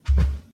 mob / cow / step1.ogg
step1.ogg